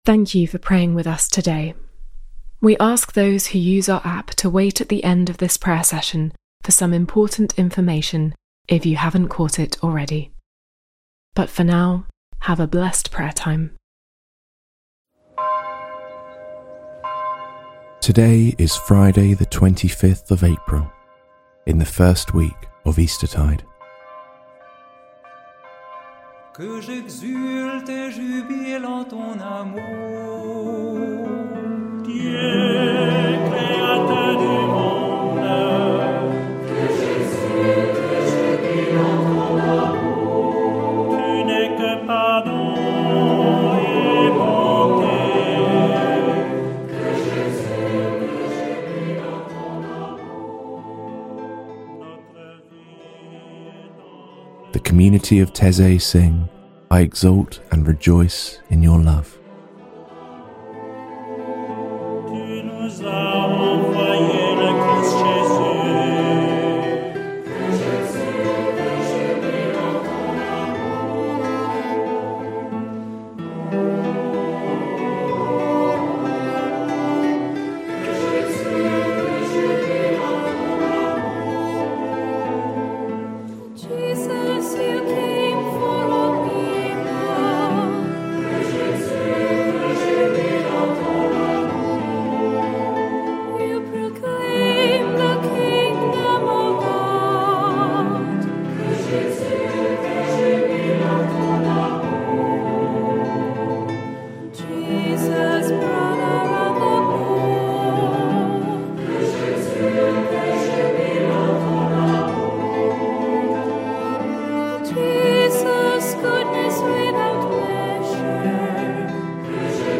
Christianity, Religion, Catholic, Meditation, Prayer, Christian, Religion & Spirituality, Self-help, Pray, Pray As You Go, Health, Praying, Spirituality, Payg